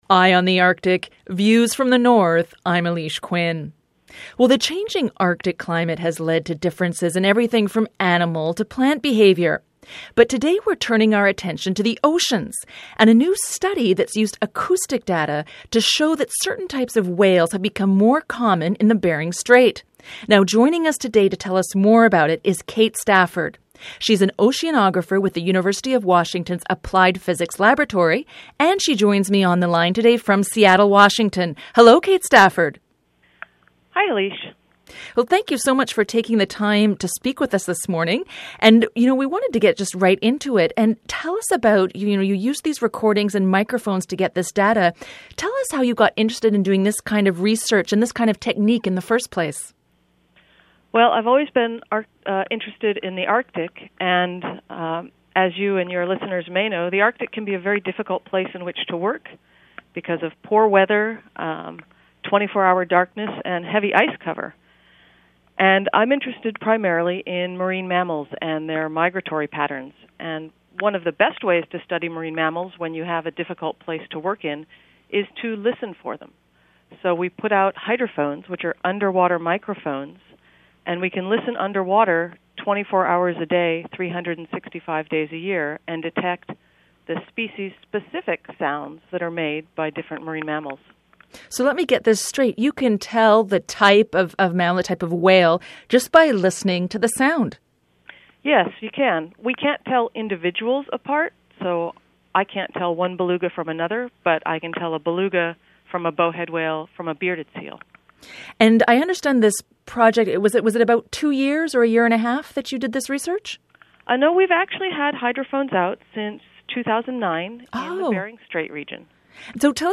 Feature Interview: What acoustics can tell us about whales in the Arctic